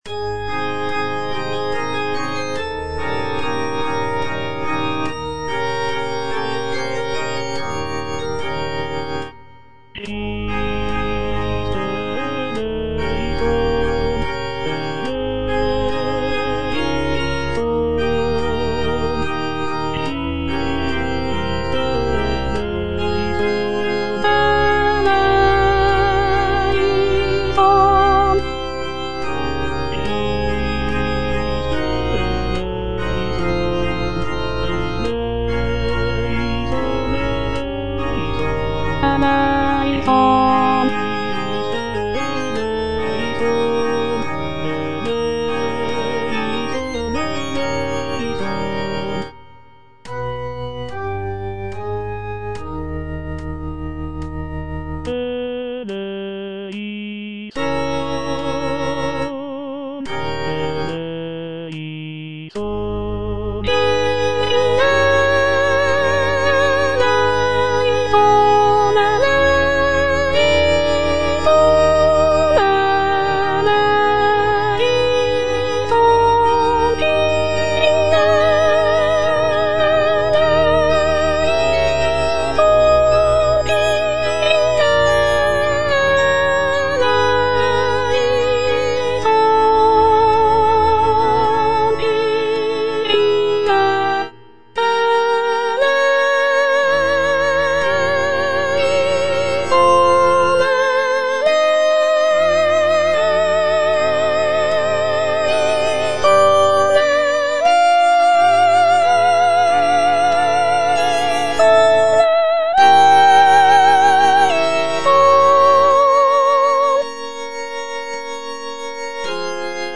C.M. VON WEBER - MISSA SANCTA NO.1 Christe eleison - Soprano (Voice with metronome) Ads stop: auto-stop
The work features a grand and powerful sound, with rich harmonies and expressive melodies.